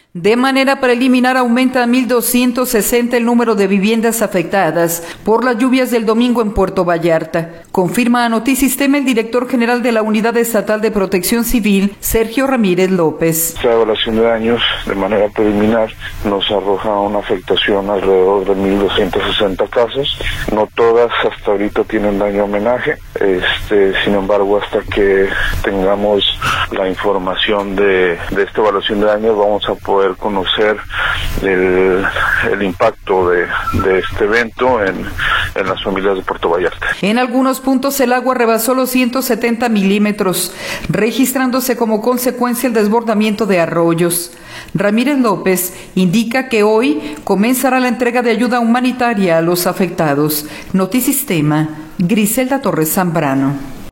De manera preliminar aumenta a 1,260 el número de viviendas afectadas por las lluvias del domingo en Puerto Vallarta, confirma a Notisistema el director general de la Unidad Estatal de Protección Civil, Sergio Ramírez López.